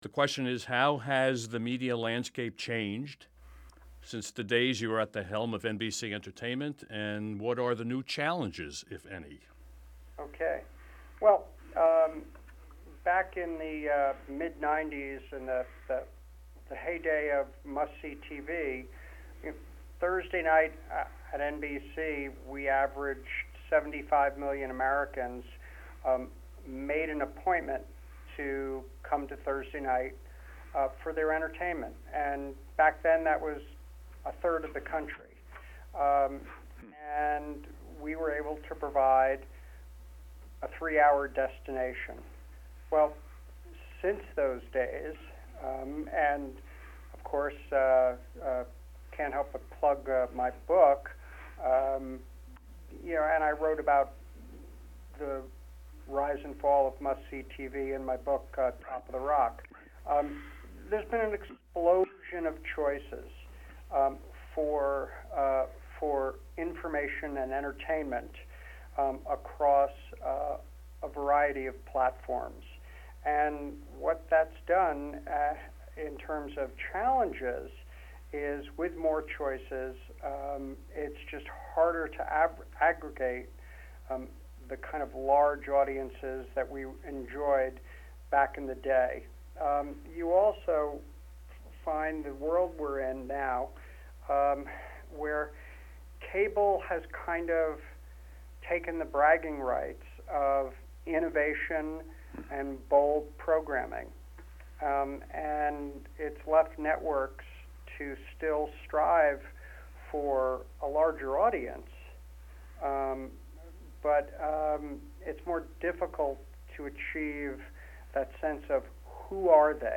An Interview with Warren Littlefield